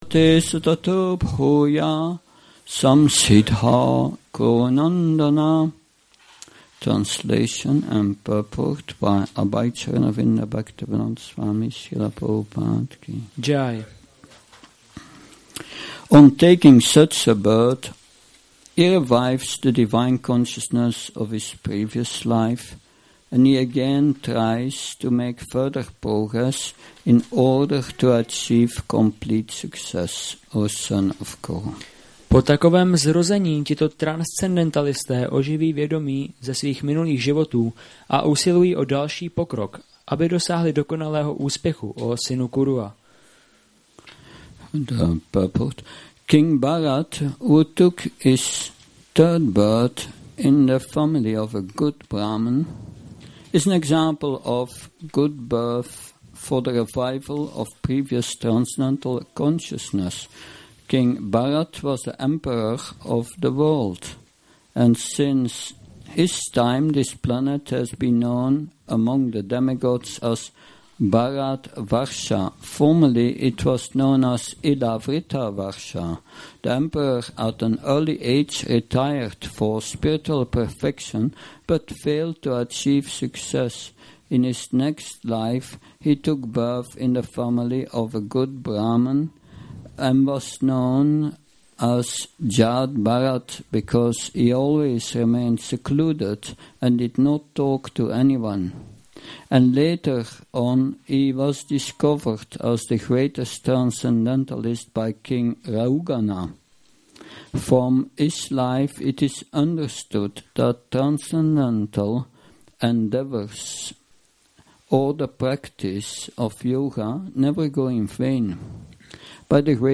Přednáška BG-6.43 – Šrí Šrí Nitái Navadvípačandra mandir